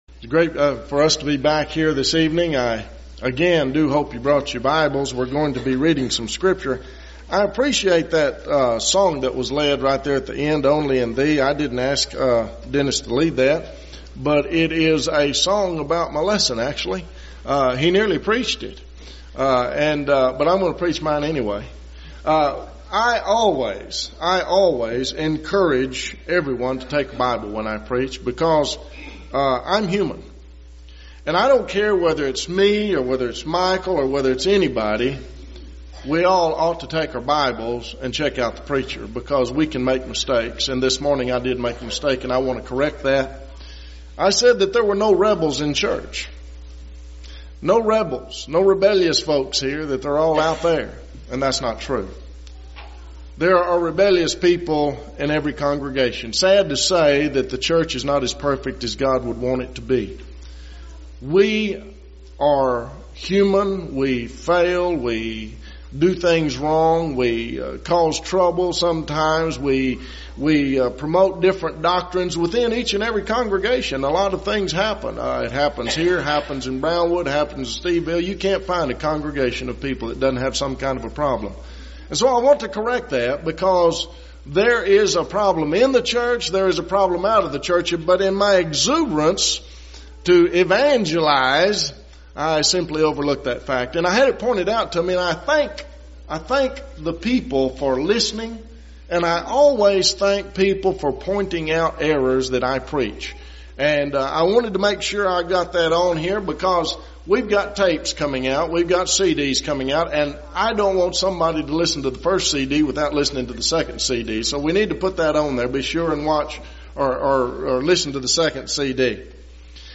Spring Gospel Meeting
lecture